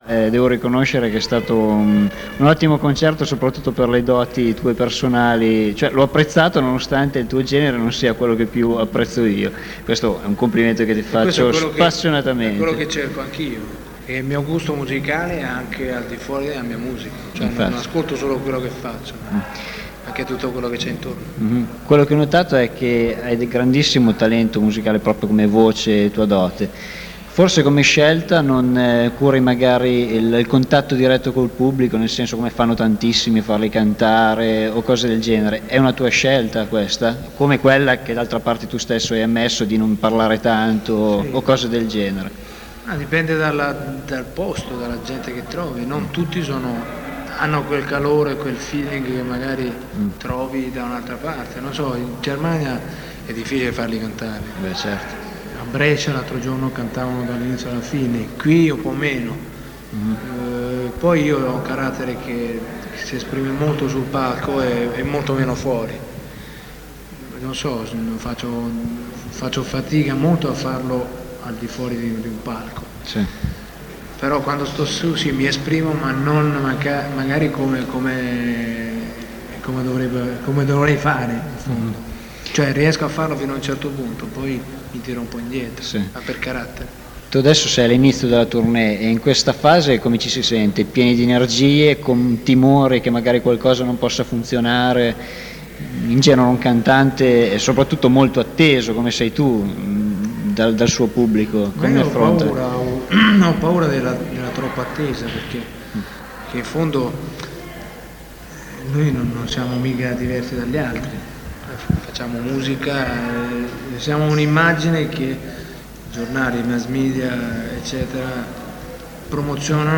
l'intervista e i saluti
Incontro esclusivo con Eros Ramazzotti nel backstage del Moccagatta, subito dopo il concerto. L’artista parla del rapporto col pubblico durante i live, la tensione del palco, il confronto con i budget delle star straniere, il duetto più ambito, l’incontro con Zucchero a Castrocaro nel 1981…